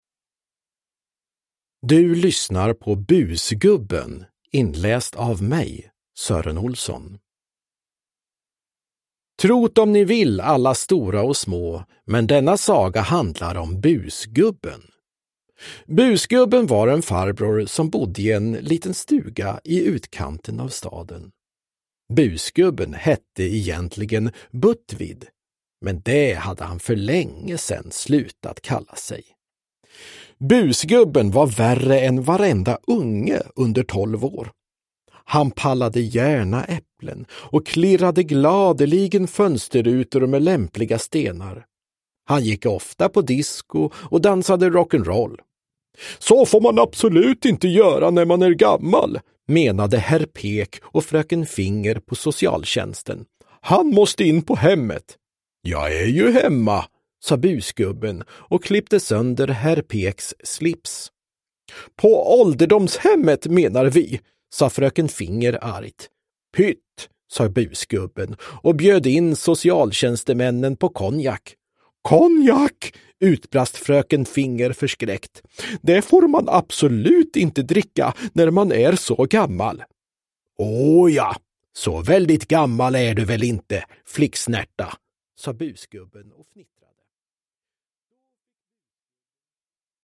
Busgubben – Ljudbok – Laddas ner
Uppläsare: Sören Olsson, Anders Jacobsson